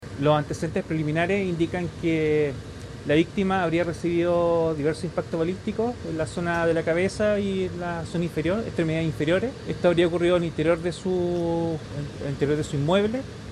indicó el comisario de la PDI